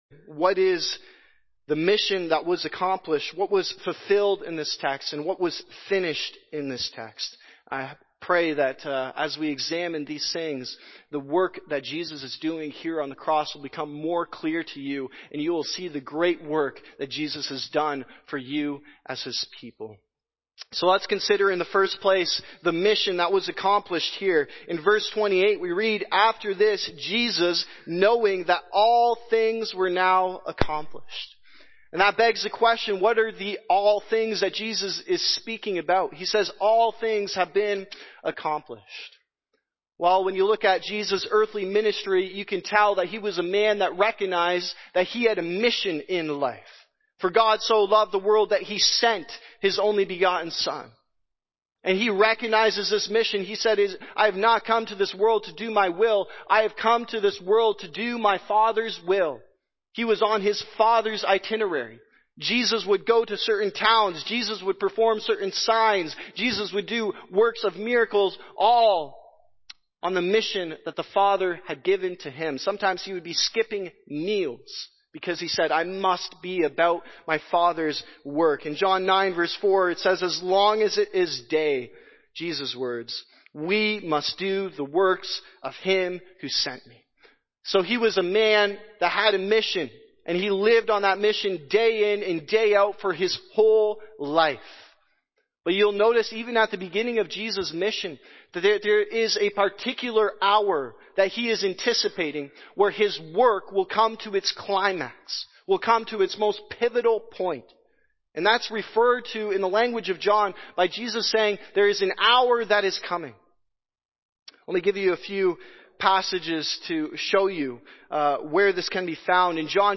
Good Friday Service – John 19 – Living Water Reformed Church
Sermon